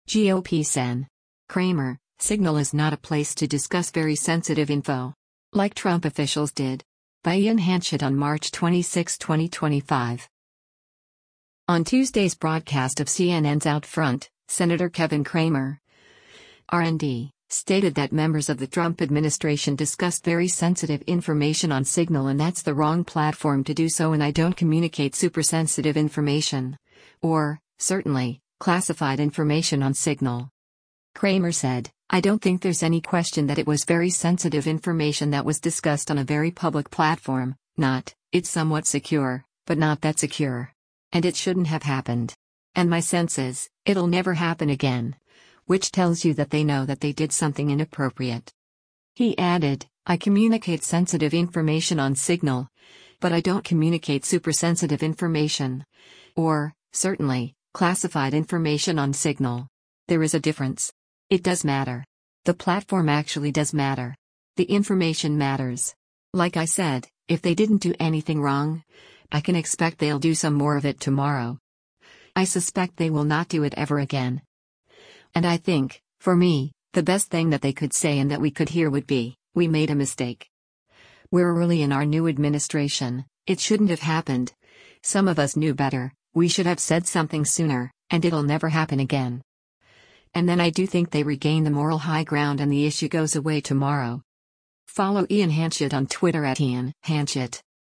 On Tuesday’s broadcast of CNN’s “OutFront,” Sen. Kevin Cramer (R-ND) stated that members of the Trump administration discussed “very sensitive information” on Signal and that’s the wrong platform to do so and “I don’t communicate super-sensitive information, or, certainly, classified information on Signal.”